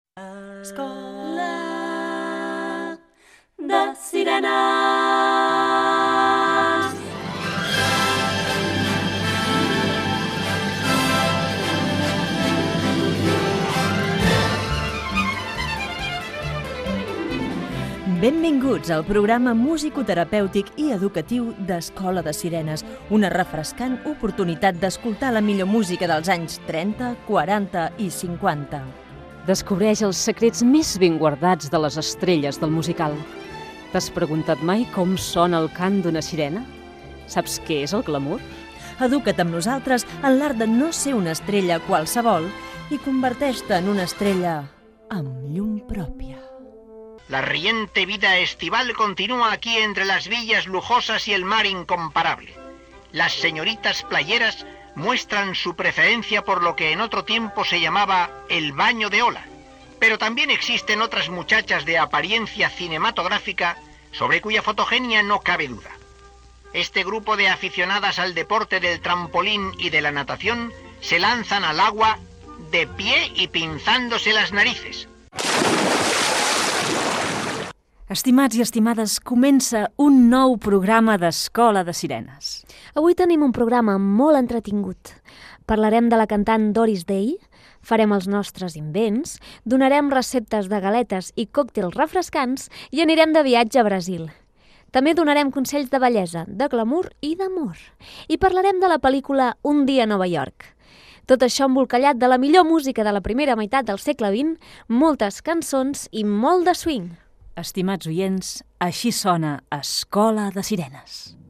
Careta, presentació i sumari del programa de música dels anys 1930, 1940 i 1950
Musical